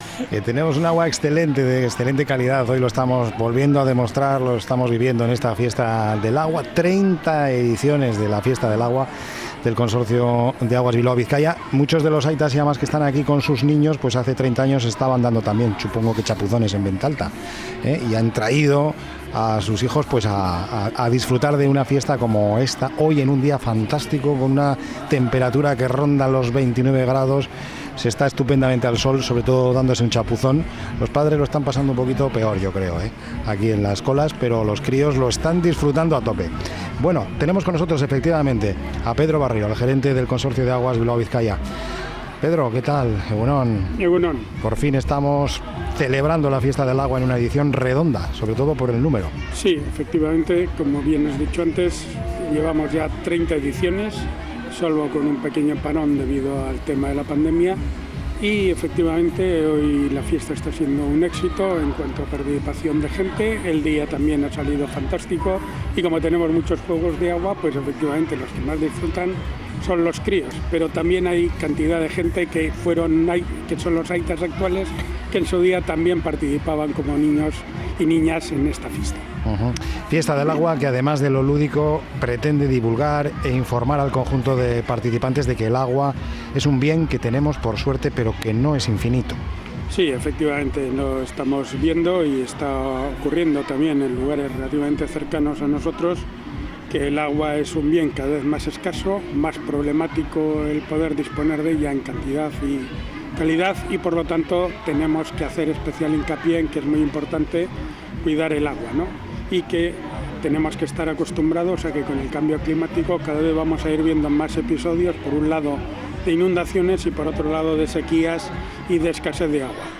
Tomando el pulso a la actualidad en el mediodía más dinámico de la radio.